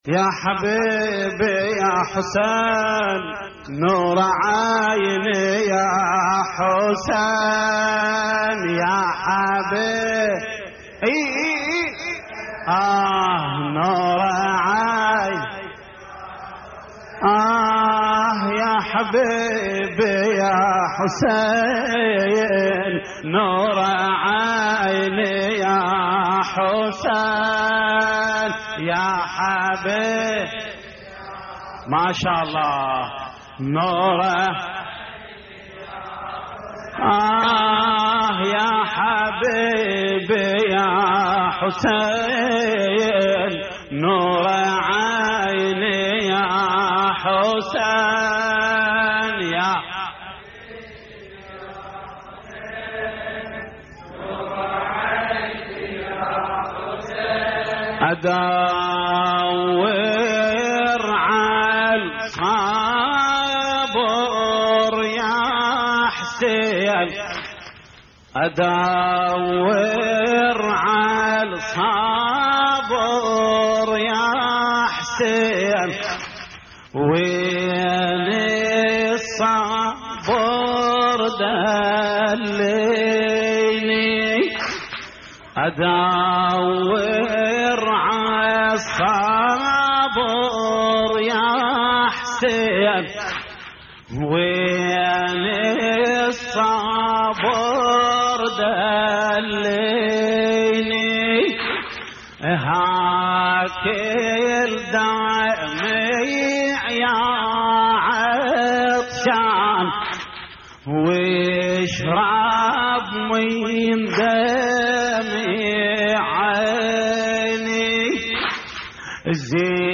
شوط كربلائي